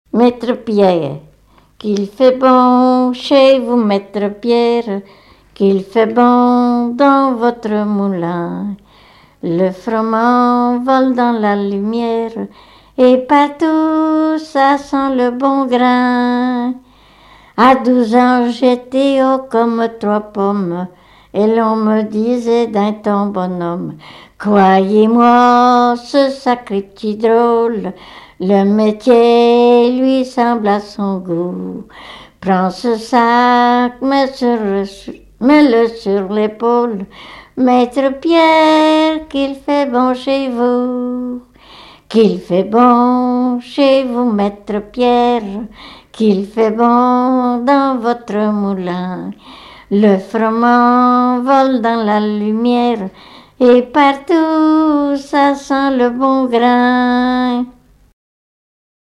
Genre strophique
Chansons traditionnelles
Pièce musicale inédite